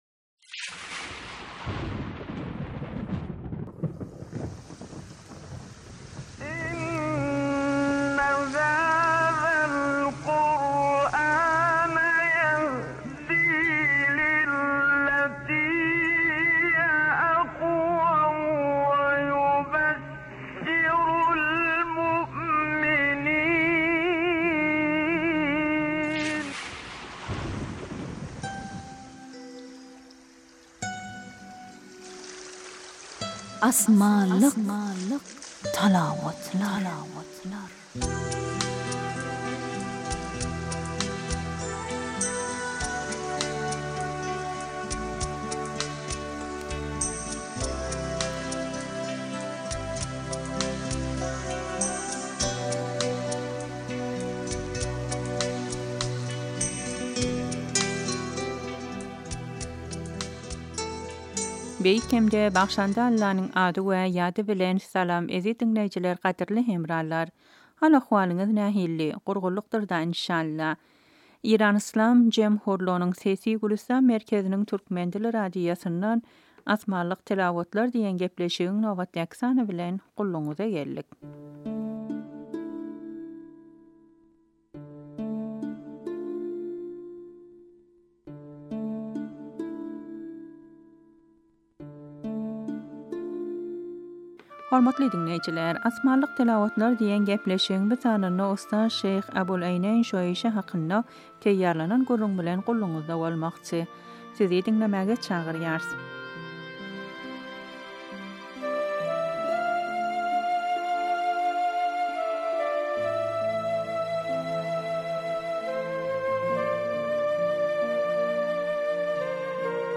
Asmanlik talawatlar
Asmanlik talawatlar : şeýh äboläýneýn şäyýşä